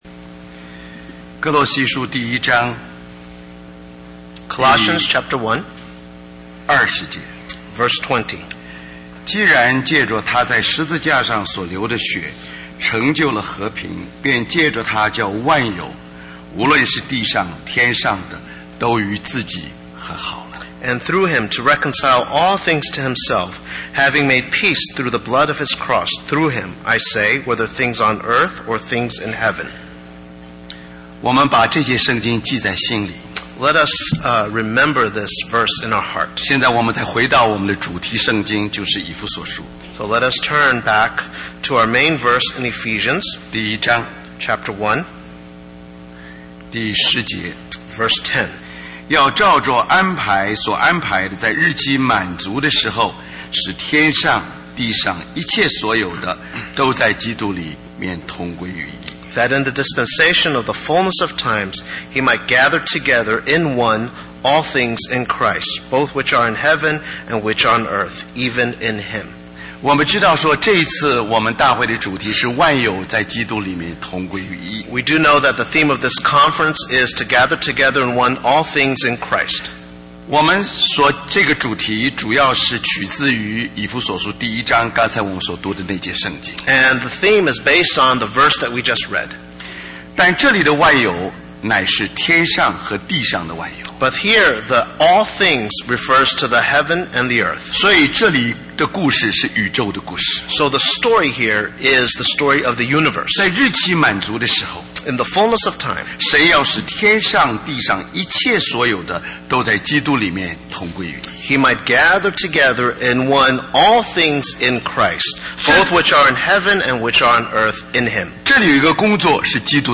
Special Conference For Service, Taipei, Taiwan